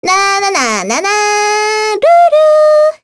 Sonia-vox-Hum_kr.wav